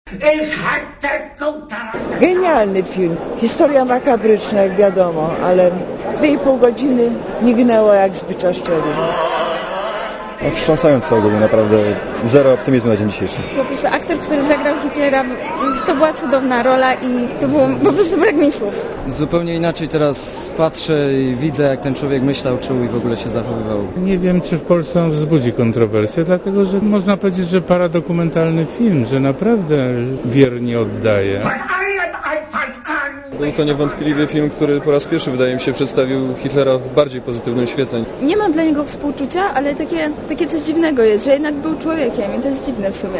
Komentarz audio
upadeksonda.mp3